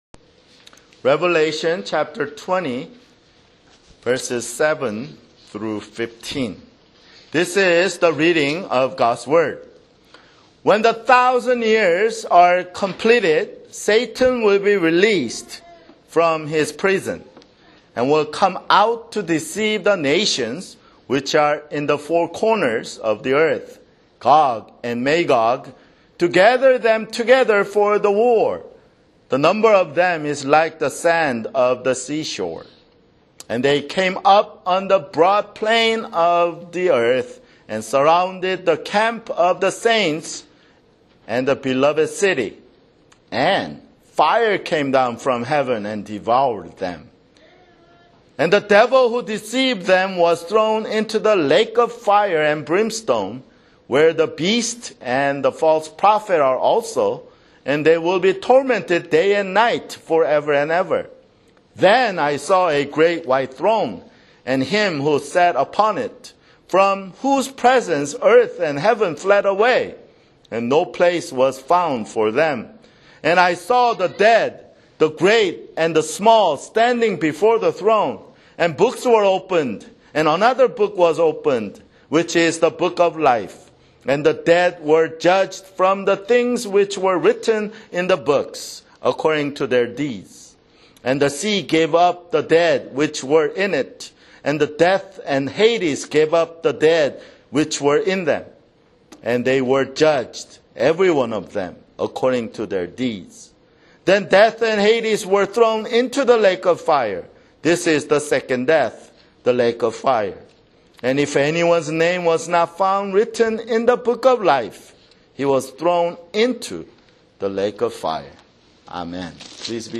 [Sermon] Revelation (79)